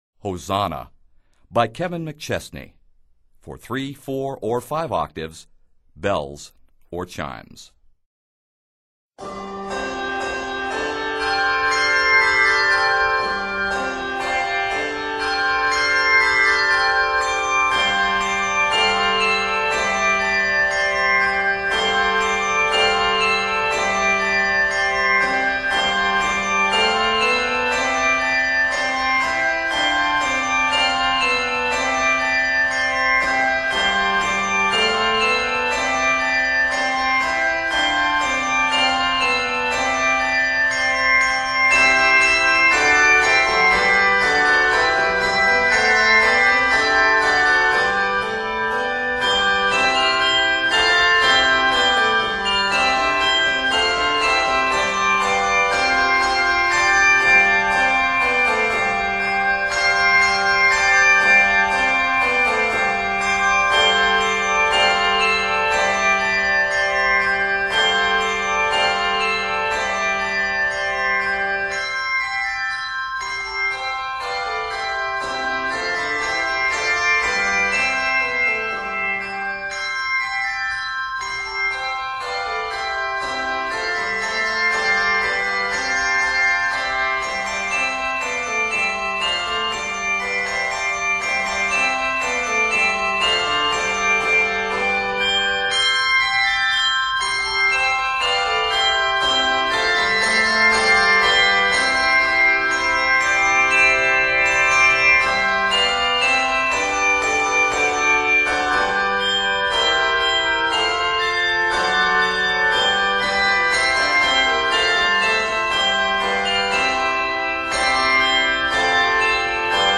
handbells
is arranged in C Major